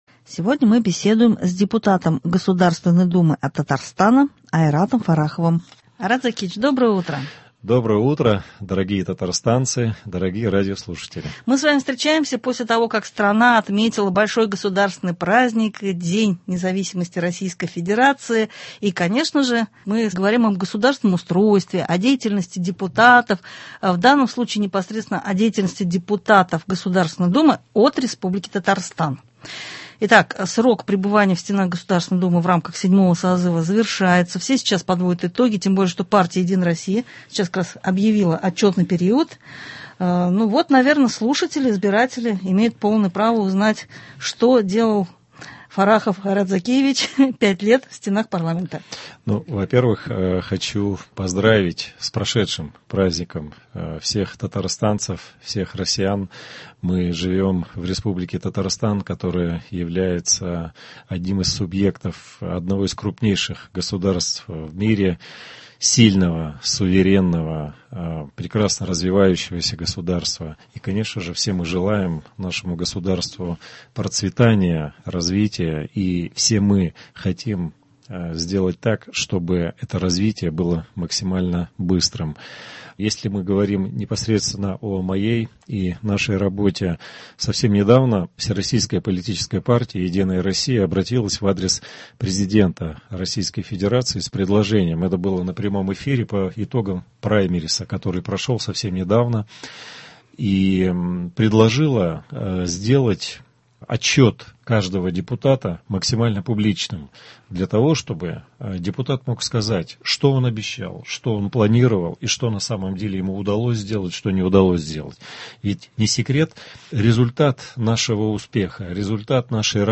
Сегодня мы беседуем с депутатом Госдумы от Татарстана Айратом Фарраховым: